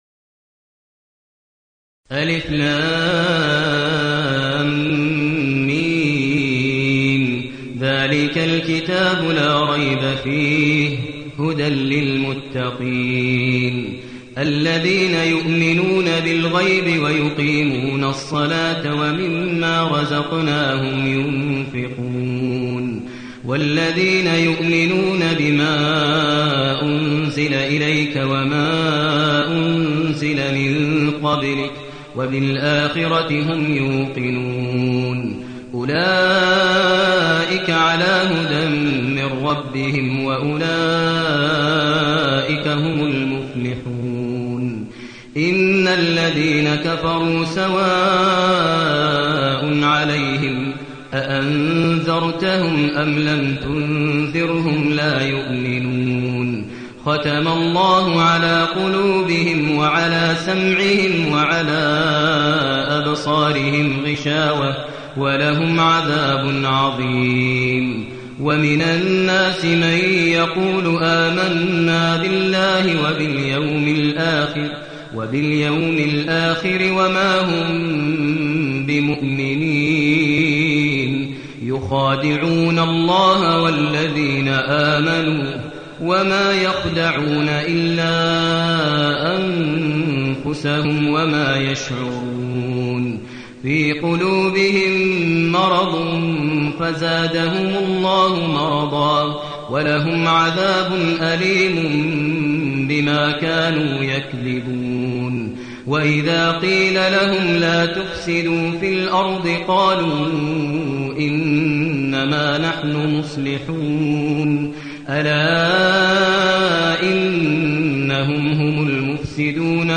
المكان: المسجد النبوي الشيخ: فضيلة الشيخ ماهر المعيقلي فضيلة الشيخ ماهر المعيقلي البقرة